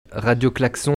prononciation